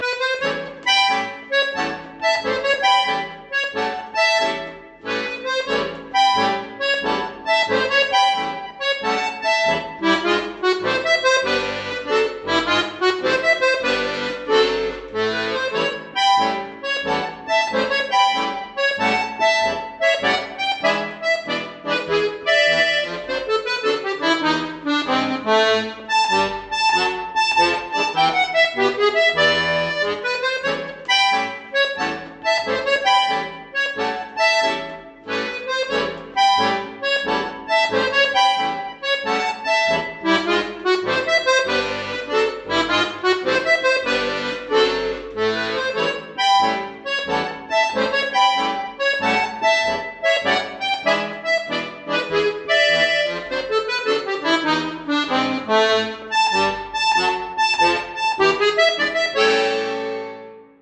Blues zu Fuß (Akkordeon-Blues)